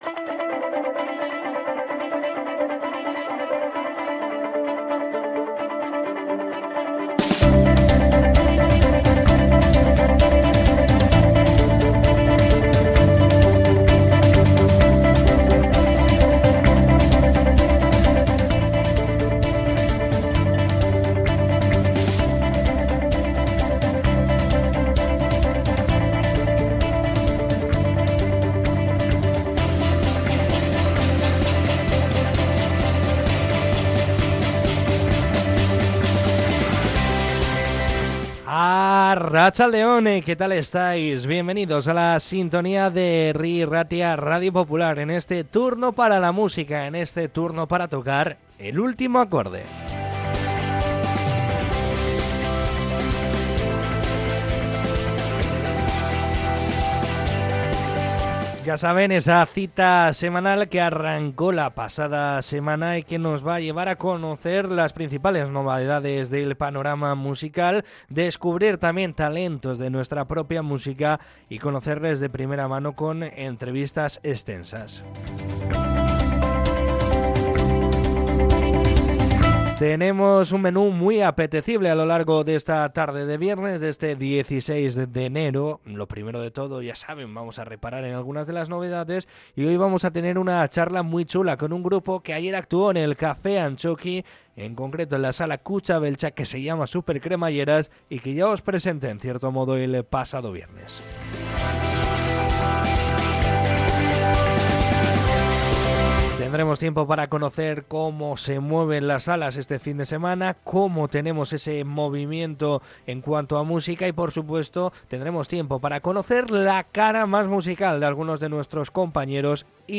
Charla con Supercremalleras, recordamos a Arriaga y las mejores novedades